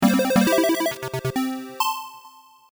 アイキャッチやジングルに使えそうな短いフレーズ
ゲームクリア_1 ステージクリア用BGM